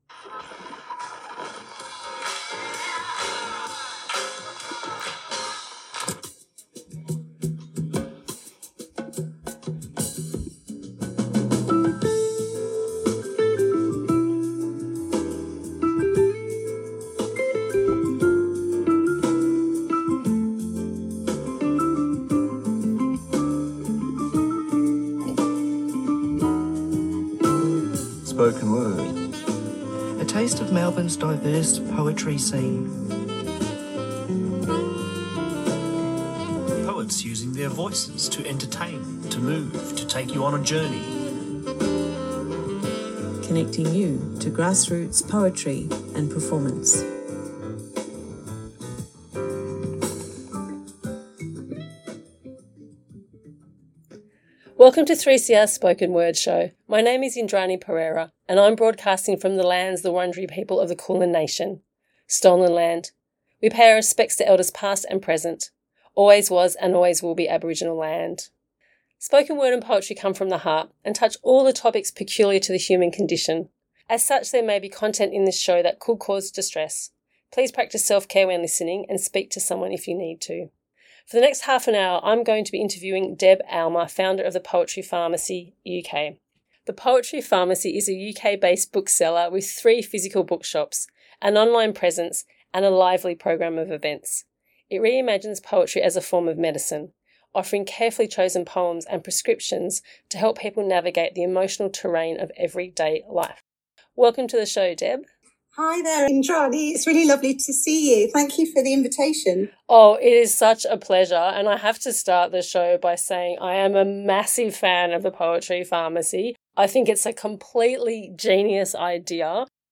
Tweet Spoken Word Thursday 9:00am to 9:30am A program dedicated to the eclectic world of poetry and performance. Guests are contemporary poets who read and discuss their works.